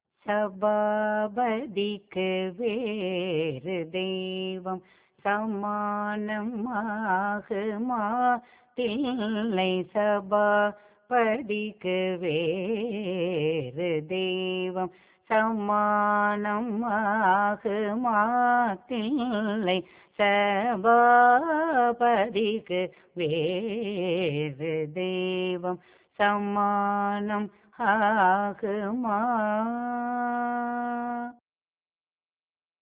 இராகம் : ஆபோகி தாளம் - ரூபகம்